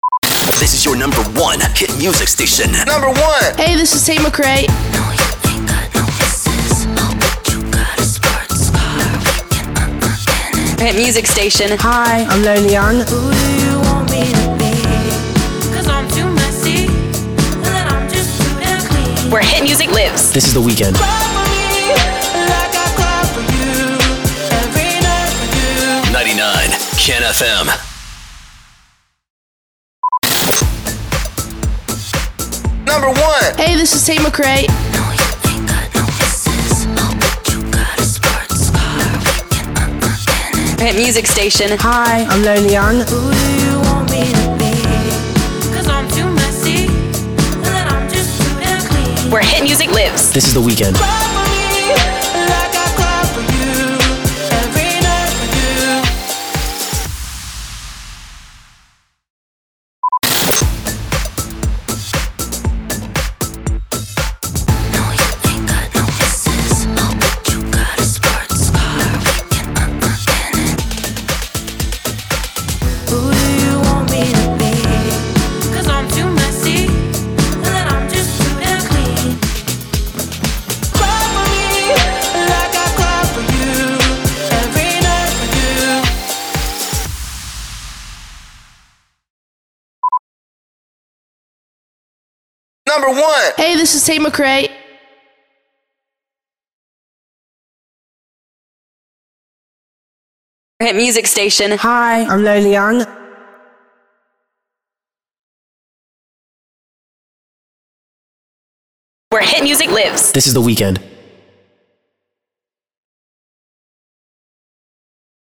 702 – SWEEPER – BEATMIX PROMO